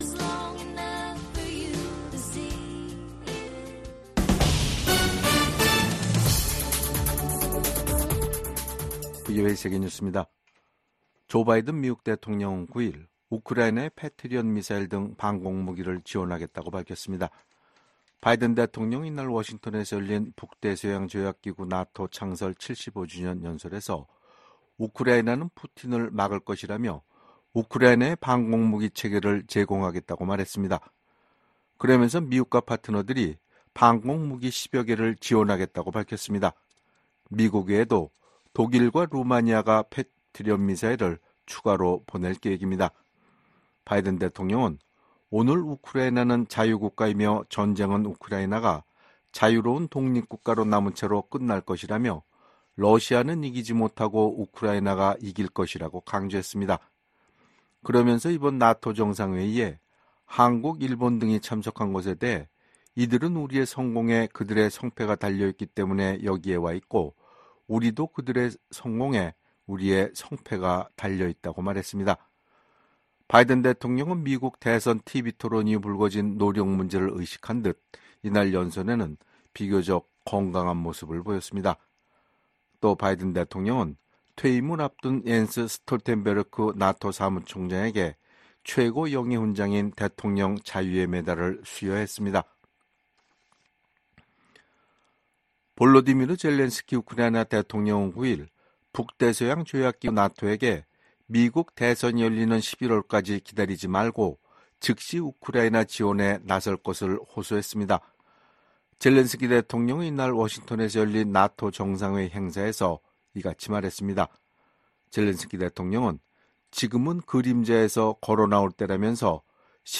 VOA 한국어 간판 뉴스 프로그램 '뉴스 투데이', 2024년 7월 10일 3부 방송입니다. 워싱턴에서 북대서양조약기구(NATO∙나토) 정상회의가 개막한 가운데 조 바이든 미국 대통령 러시아의 침략 전쟁은 실패했다고 지적했습니다. 미국 북 핵 수석대표인 정 박 대북고위관리가 최근 사임했다고 국무부가 밝혔습니다. 탈북민들이 미국 의회에서 열린 증언 행사에서 북한 주민들이 세상을 제대로 알고 변화를 주도하도록 외부 정보 유입을 강화해야 한다고 촉구했습니다.